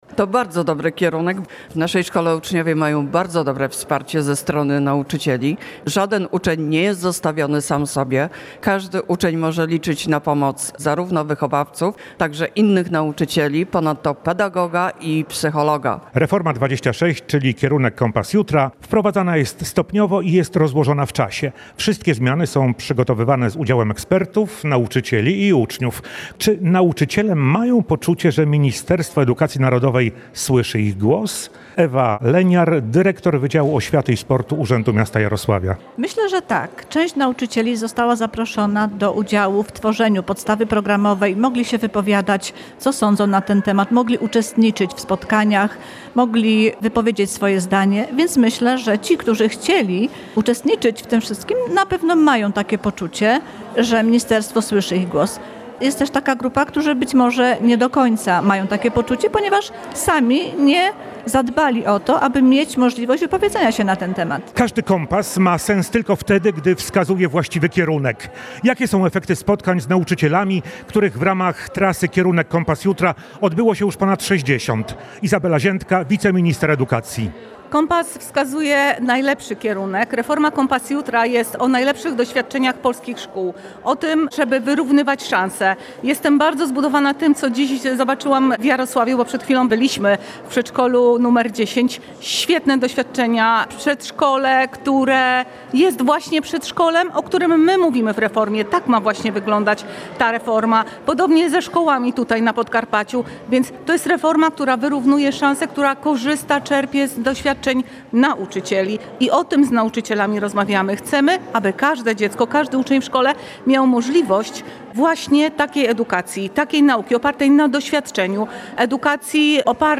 – powiedziała Radiu Rzeszów wiceminister edukacji Izabela Ziętka.